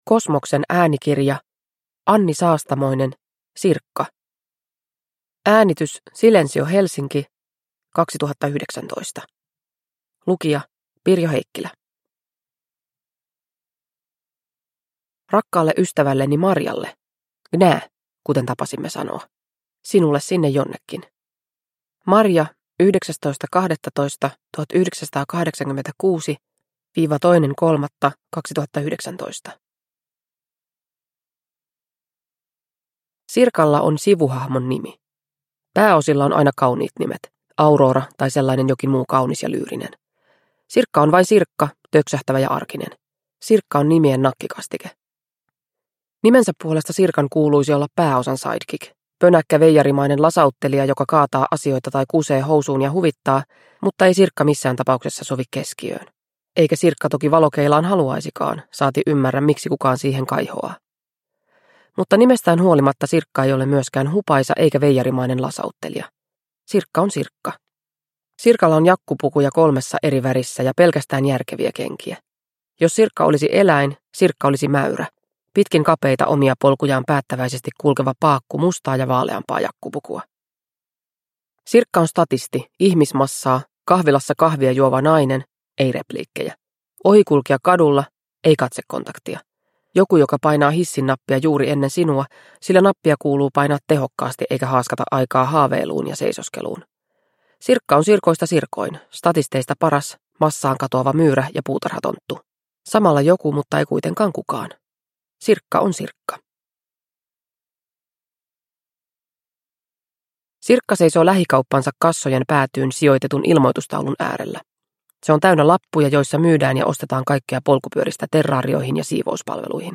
Sirkka – Ljudbok – Laddas ner